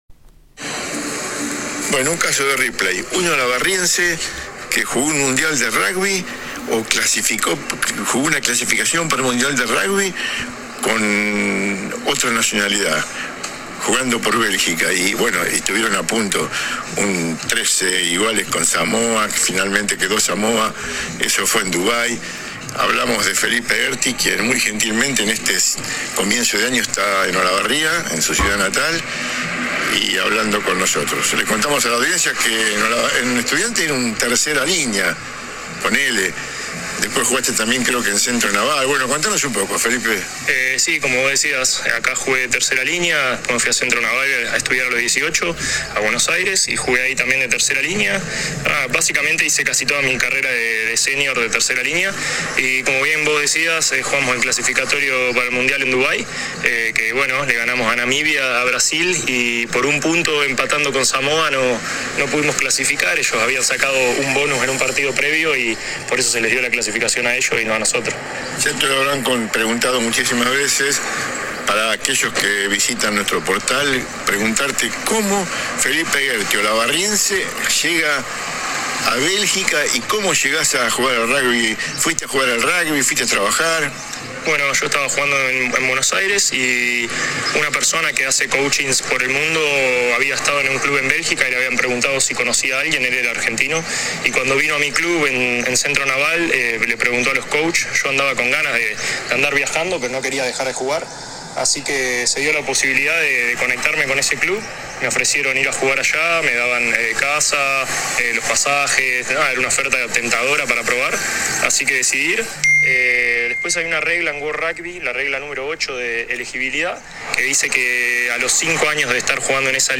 AUDIOS DE LA ENTREVISTA